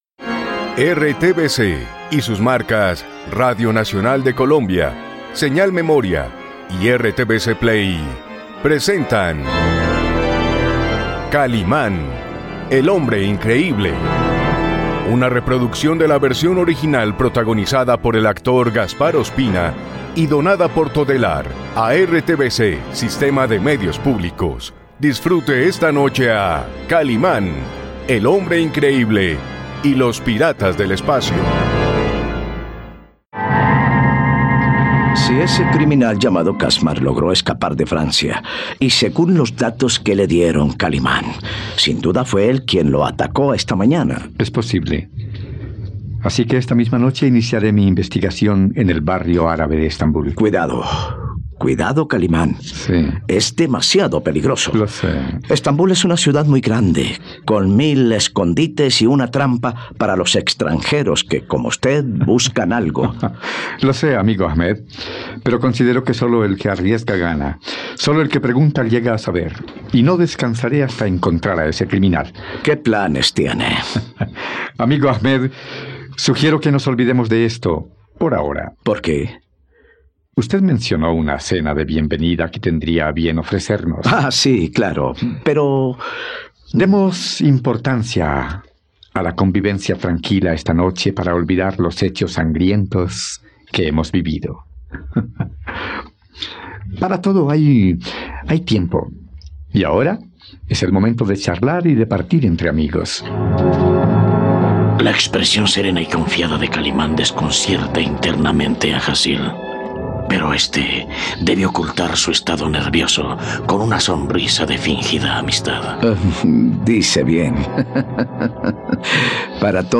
..Disfruta de una nueva aventura junto al hombre increíble, en RTVCPlay puedes disfrutar de la radionovela completa de 'Kalimán y los piratas del espacio'.